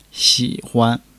xi3-huan.mp3